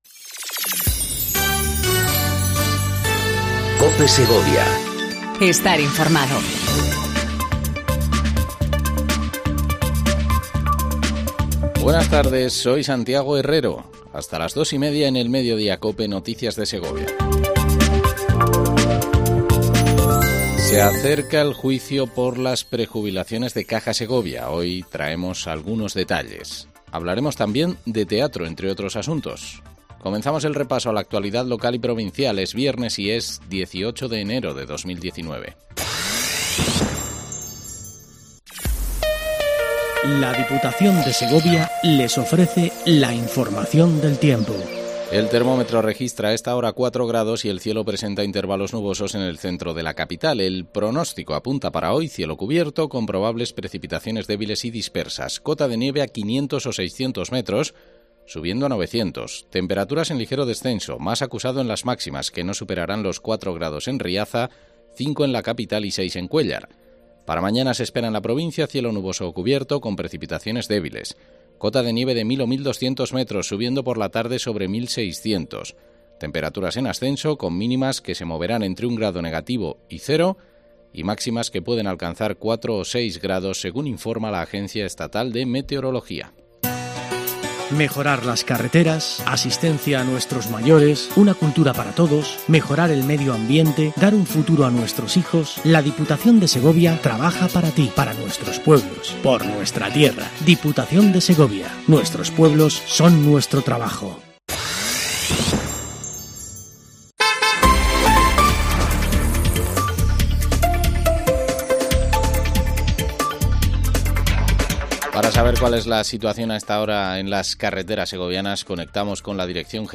INFORMATIVO DEL MEDIODÍA EN COPE SEGOVIA 14:20 DEL 18/01/19